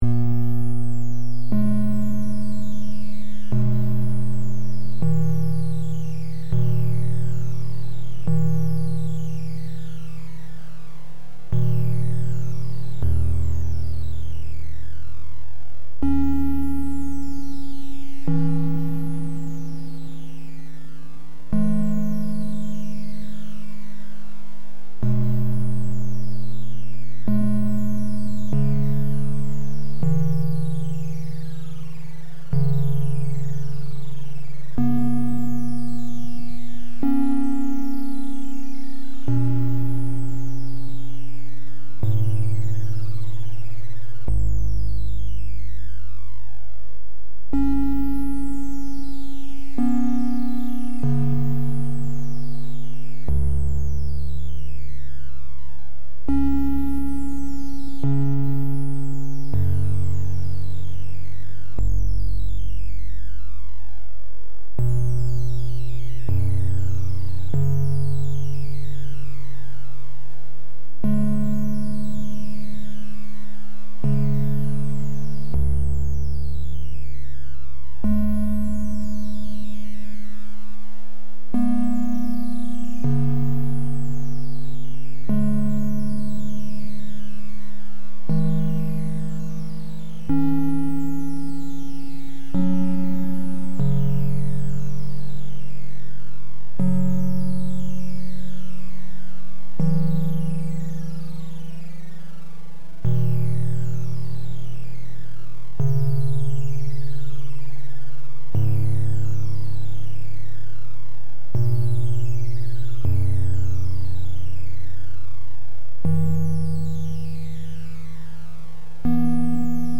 It can keep playing pseudo random tones forever, though it might loop after a few decades.
Some notes on running it: It should chime about once a second.
The added features are triangle waves, two note chords, amplitude decay, and anti-pop.
Since the output is a single byte, this is real 8 bit music. But this particular sound is a bit more mellow than other chip tunes around.
awkbient.mp3